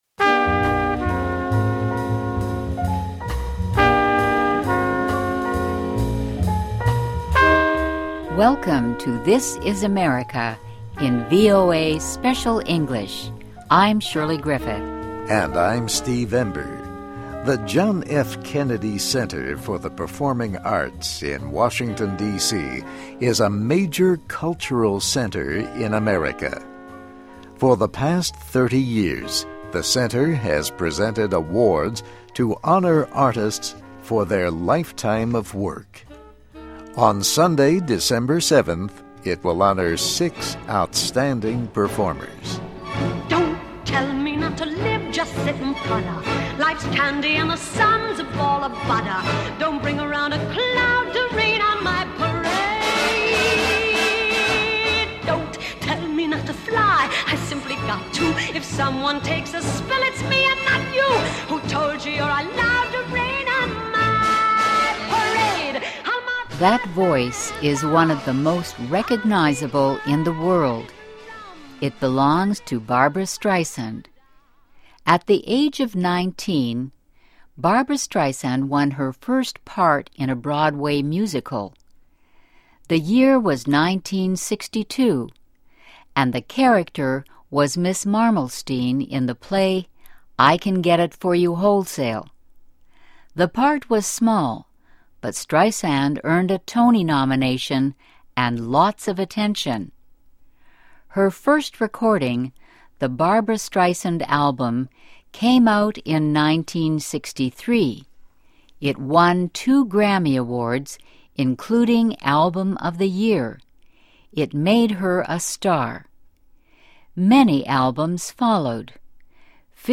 USA: Kennedy Center Honors Six Artists for Life's Work (VOA Special English 2008-12-01)<meta name="description" content="Text and MP3 File.
Listen and Read Along - Text with Audio - For ESL Students - For Learning English